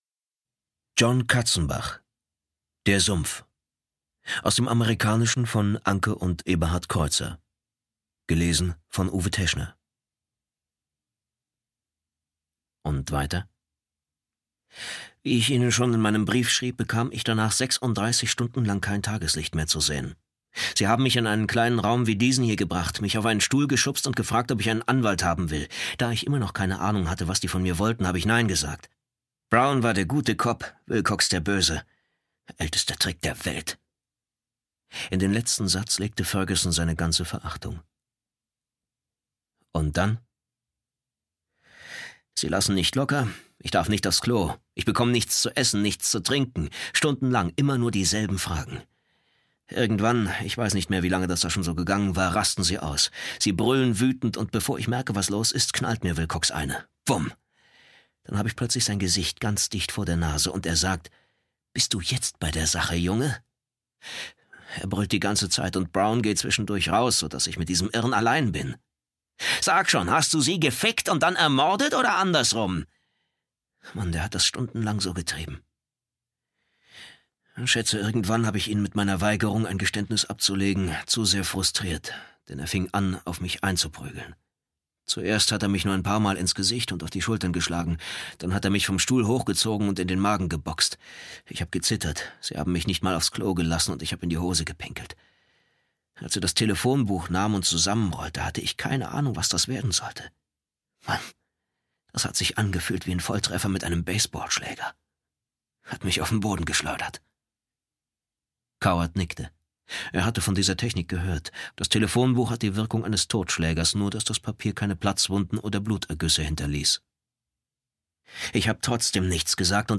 Psychothriller